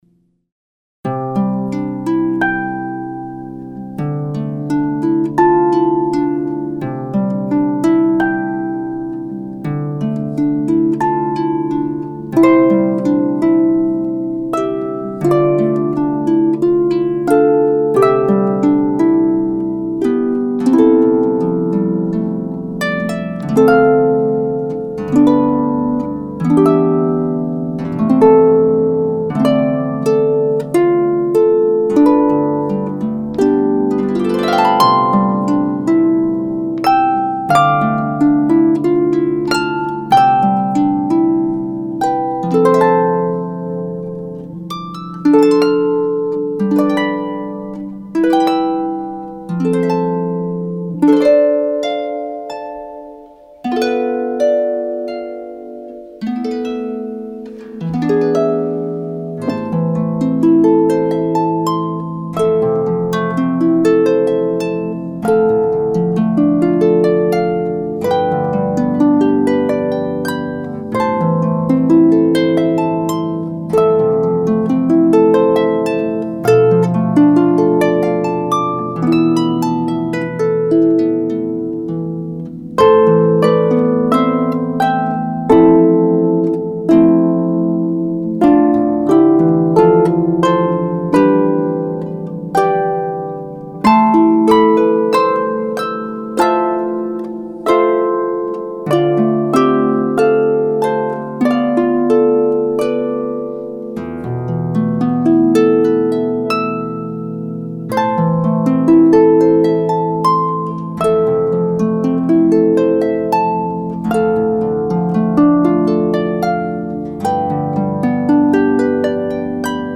In the key of C Major with one accidental.